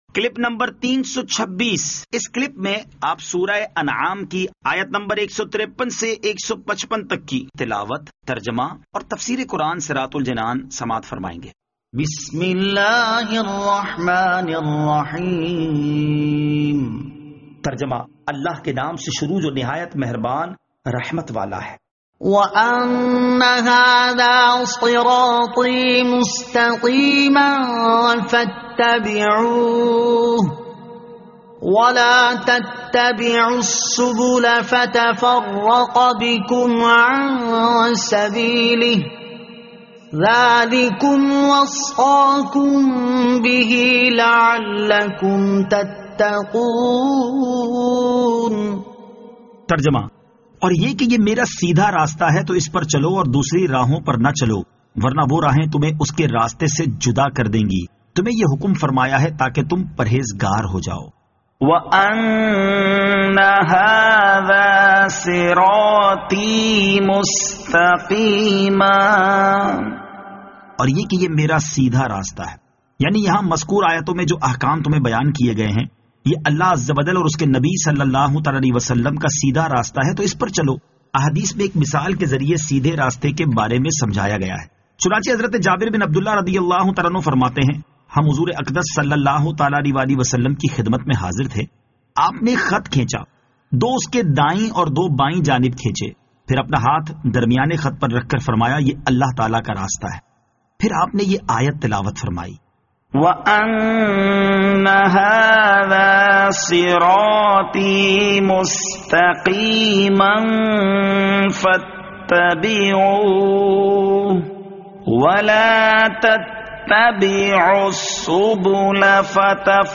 Surah Al-Anaam Ayat 153 To 155 Tilawat , Tarjama , Tafseer